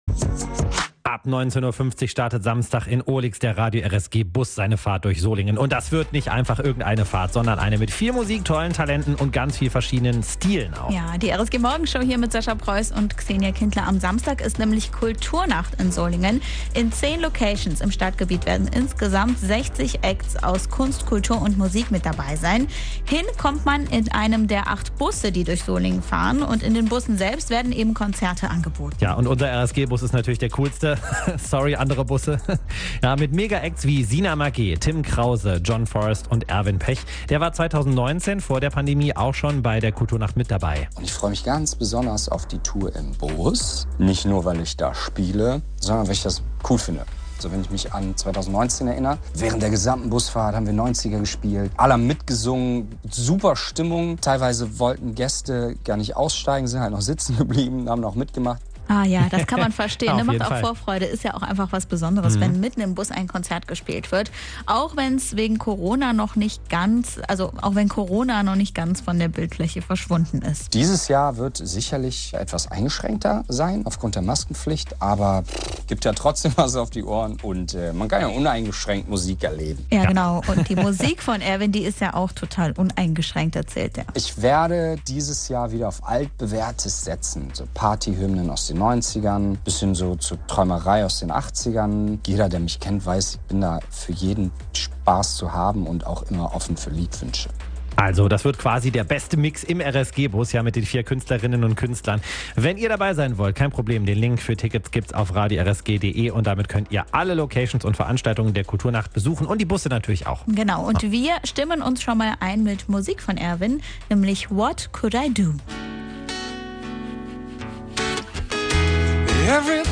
Soul, Blues, Rock und Pop
Im Gepäck: immer seine Gitarre und ein offenes Ohr für Spontaneität. https
Kultur Nacht Solingen 2022